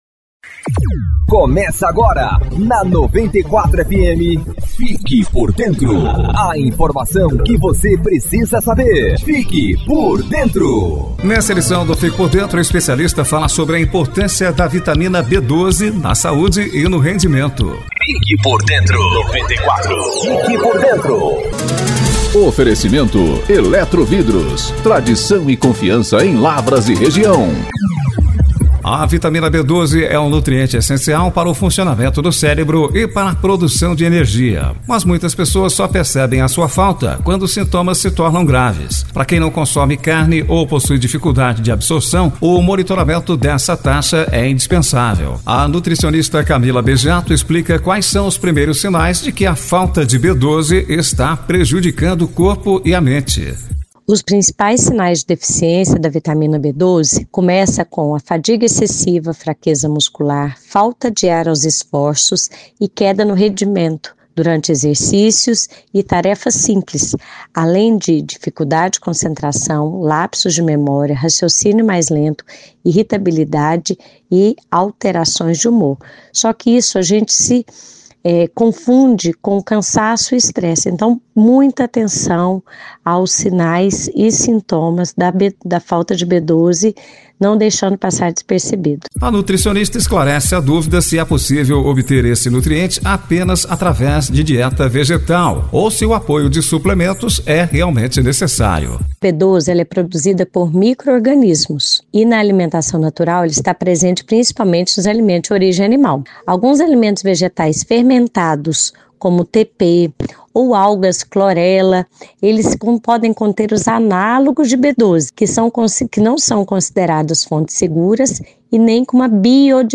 Em entrevista ao programa “Fique Por Dentro”